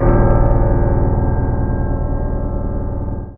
55y-pno02-a#1.aif